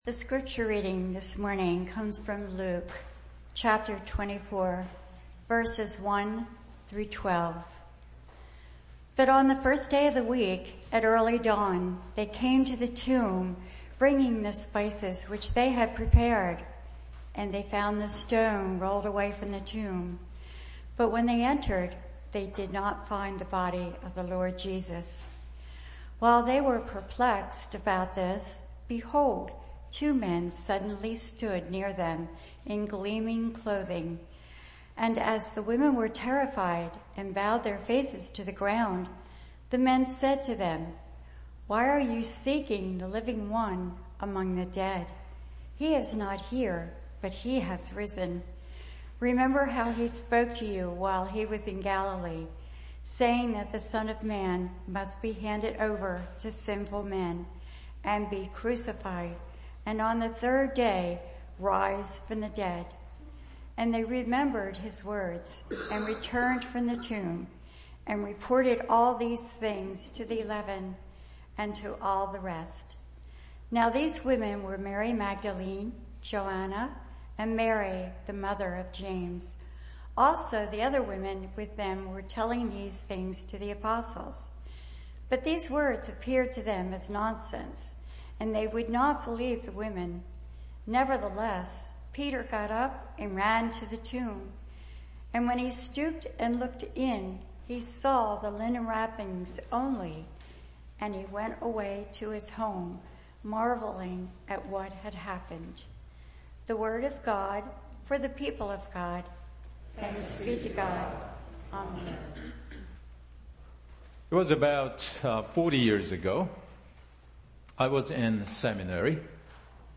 Sermon: Grow in Christlikeness (5):Faith